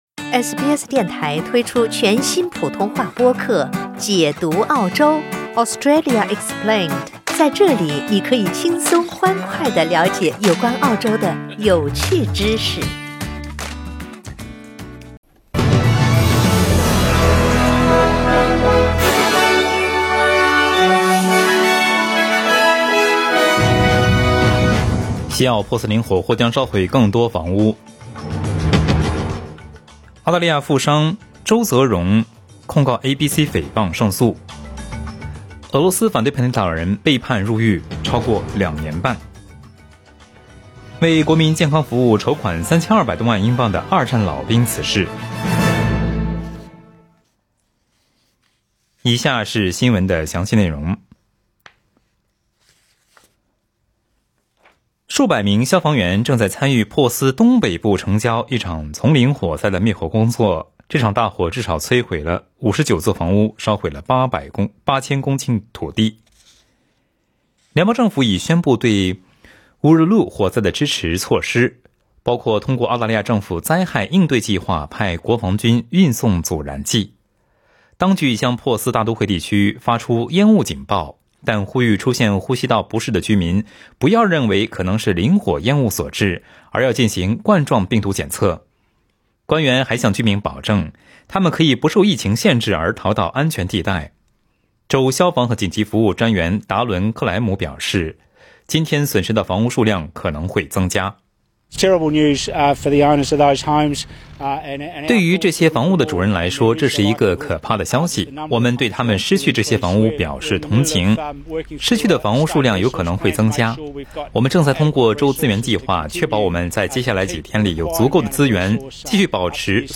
SBS早新闻（2月3日）
SBS Mandarin morning news Source: Getty Images